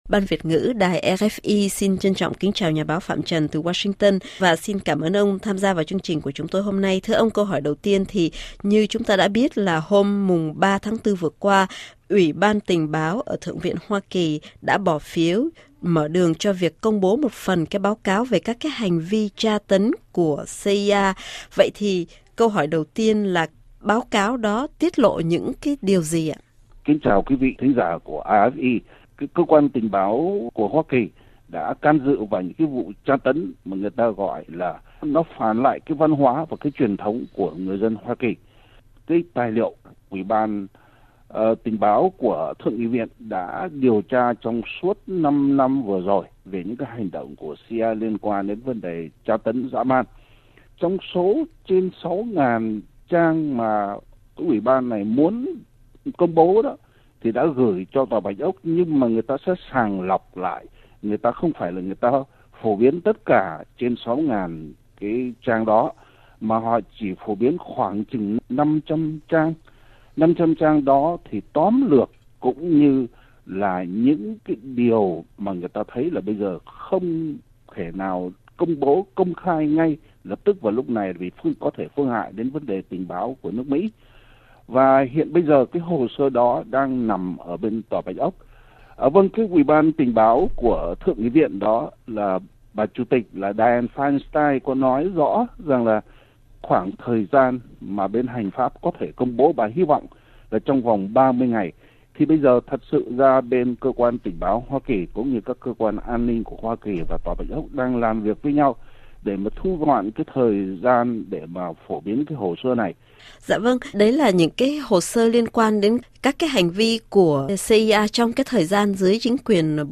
RFI phỏng vấn